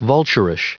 Prononciation du mot vulturish en anglais (fichier audio)